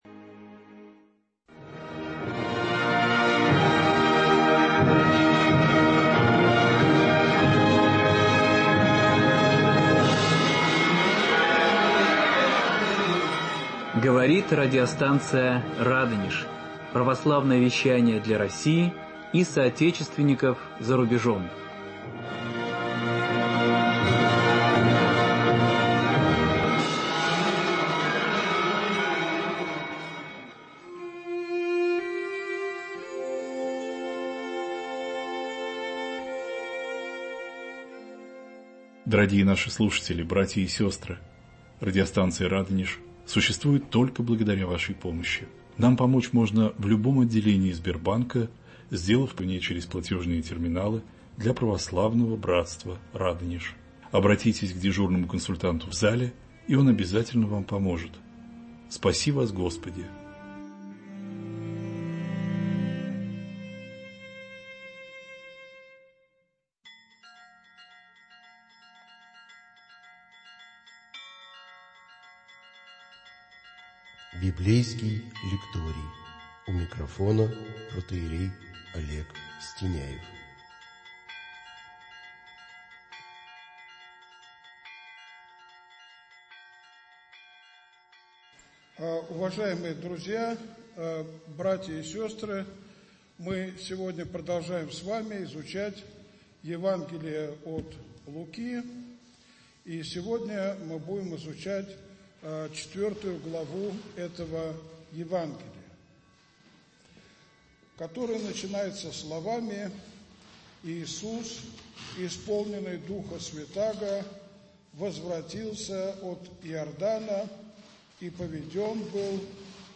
Библейский лекторий.